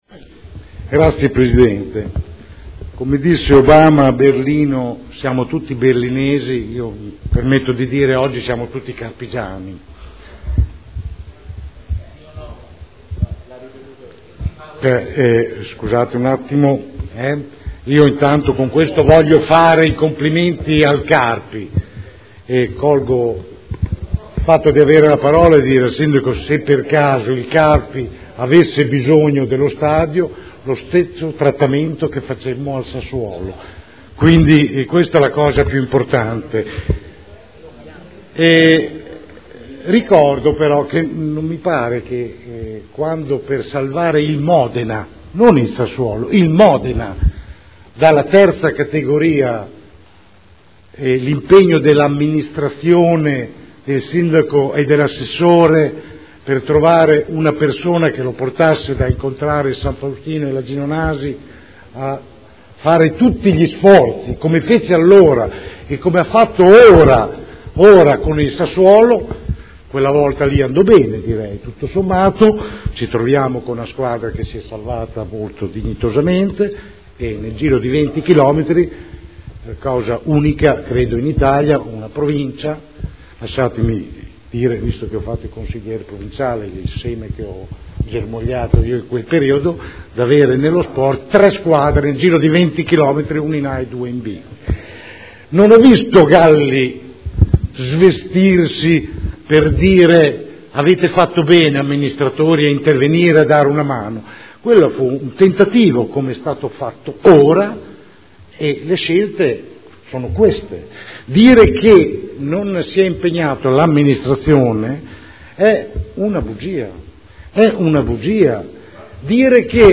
Francesco Rocco — Sito Audio Consiglio Comunale